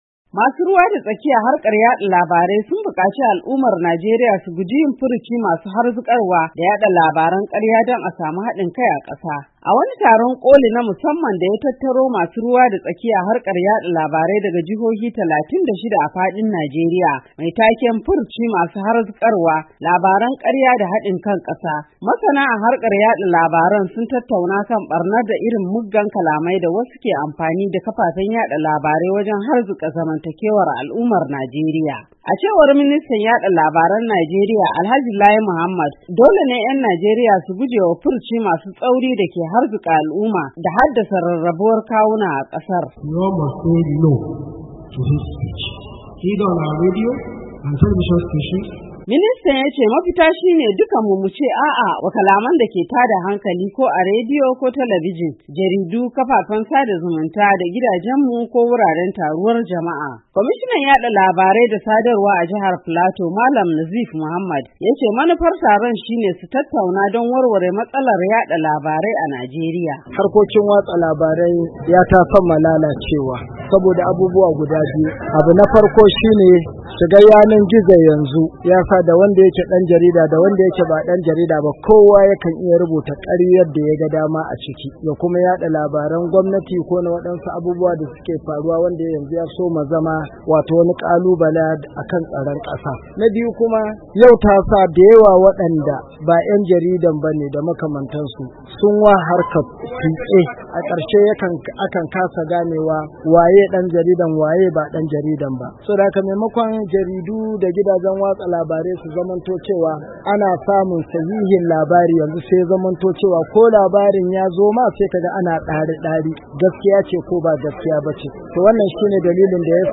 An gudanar da wani taron koli na musaman da ya hada masu ruwa da tsaki a harkar yada labarai daga jihohi 36 na Najeriya, mai taken ‘Furuci Masu Harzukarwa Labaran ‘Karya da Hadin Kan ‘Kasa’.